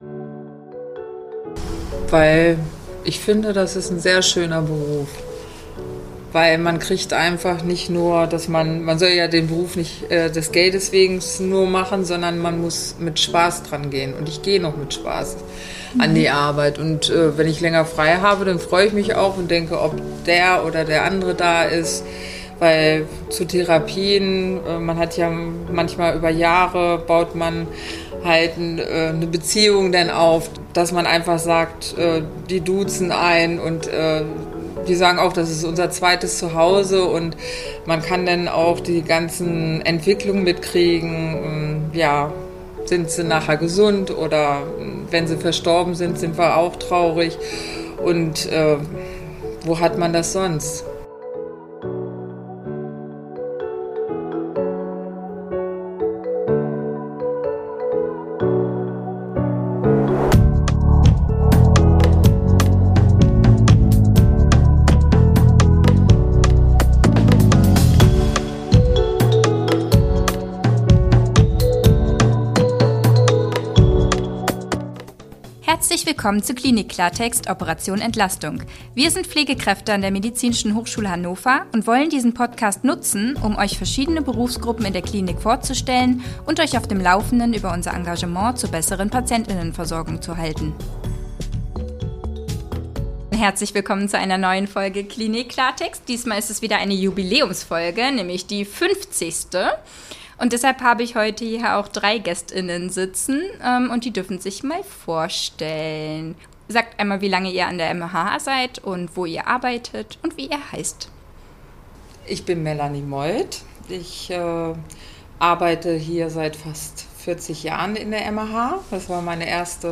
In diesem Interview